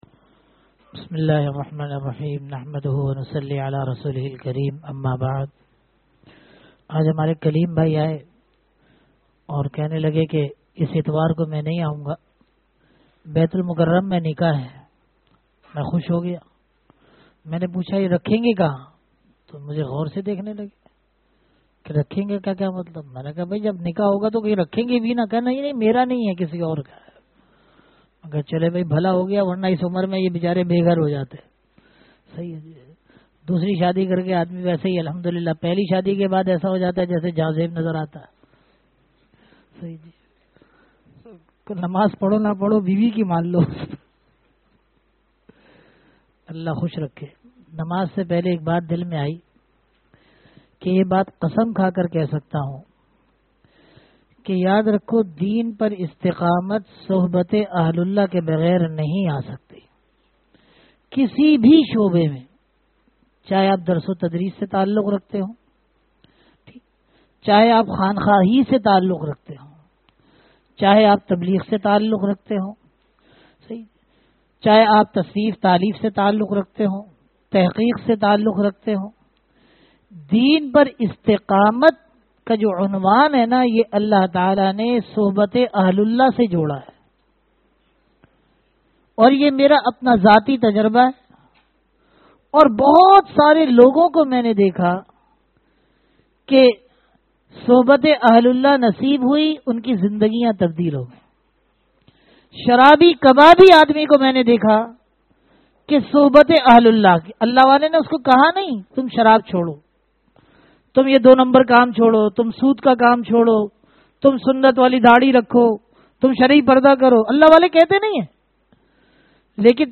Bayanat